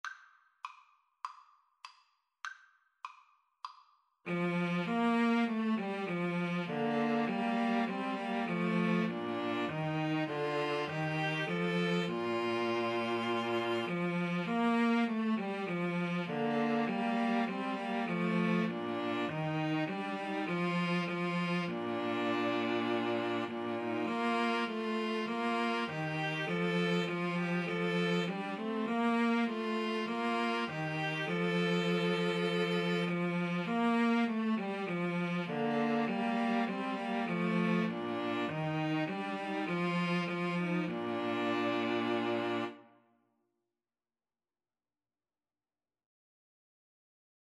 Free Sheet music for String trio
Bb major (Sounding Pitch) (View more Bb major Music for String trio )
Classical (View more Classical String trio Music)